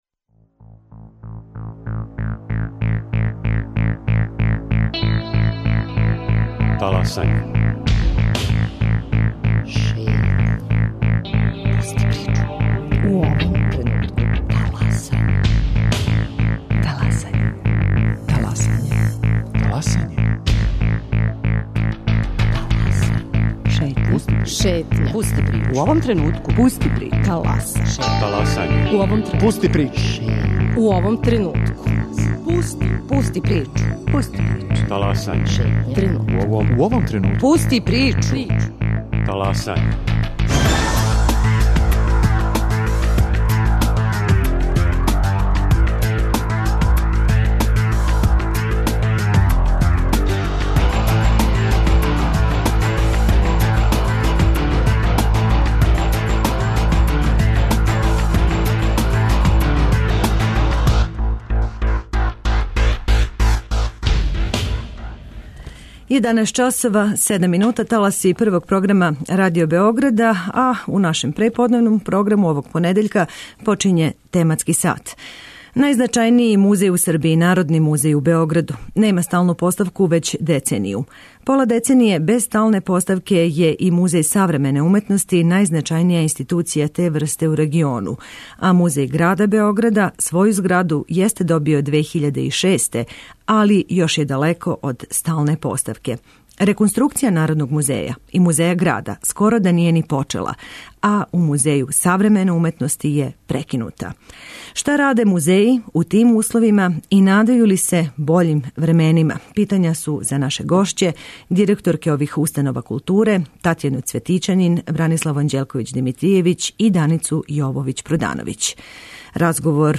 Радио Београд 1, 11.05